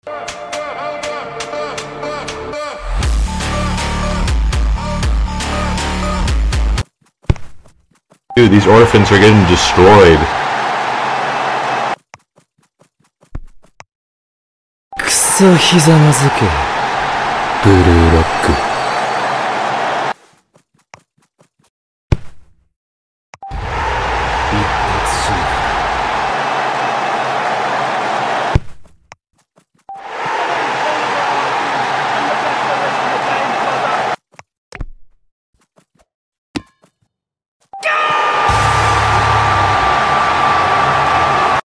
Top Ten Best Goal Sound sound effects free download